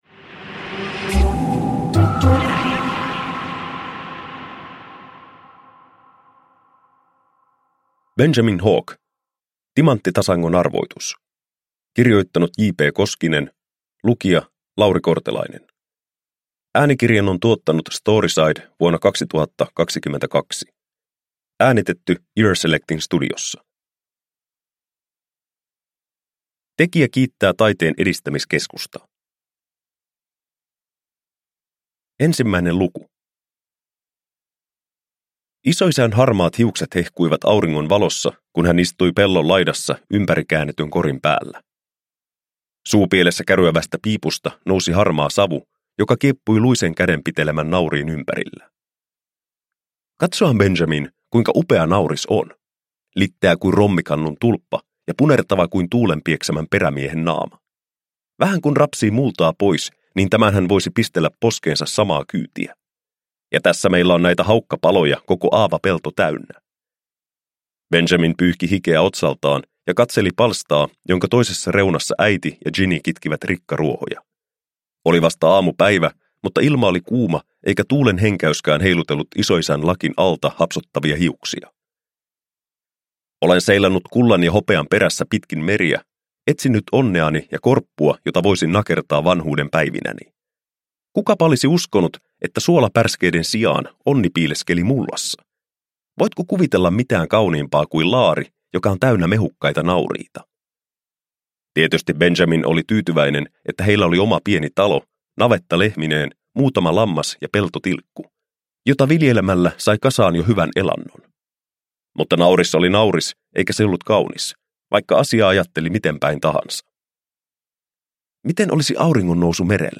Benjamin Hawk – Timanttitasangon arvoitus – Ljudbok – Laddas ner